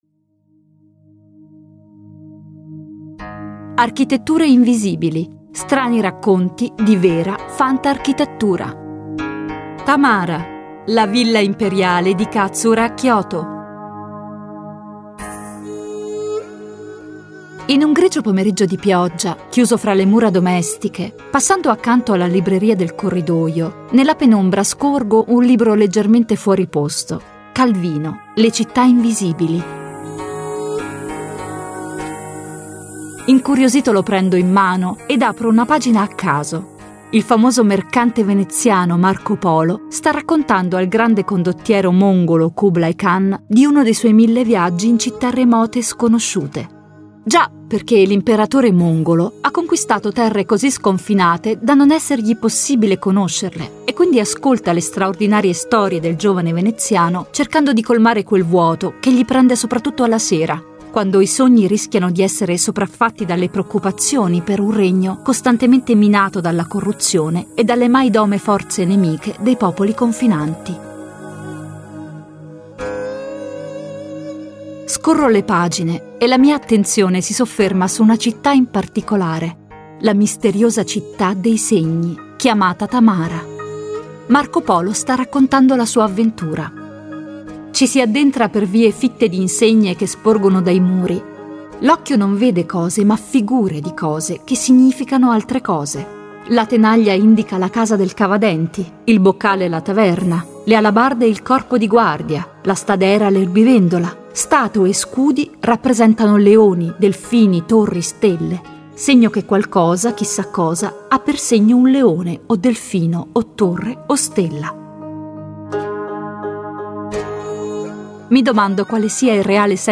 Una passeggiata alla scoperta della più iconica opera architettonica del Giappone (anteprima)